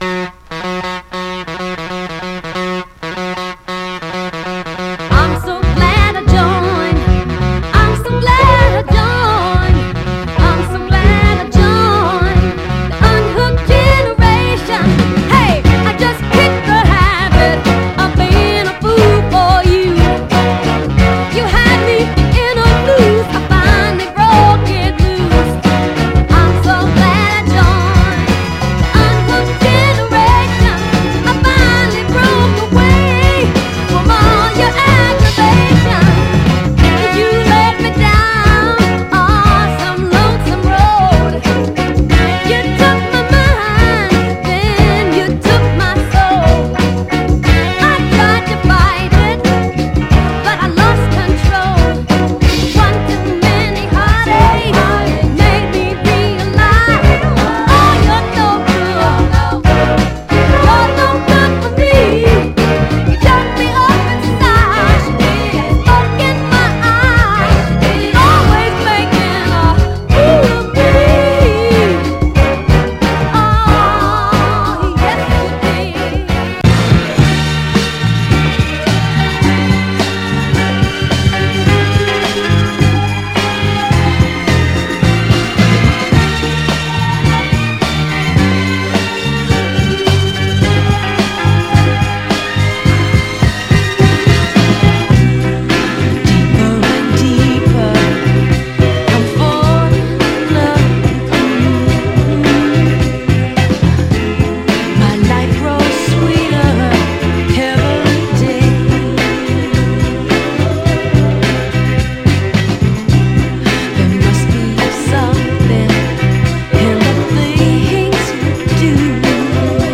序盤で僅かにチリつきますが、目立つノイズは少なくプレイ概ね良好です。
※試聴音源は実際にお送りする商品から録音したものです※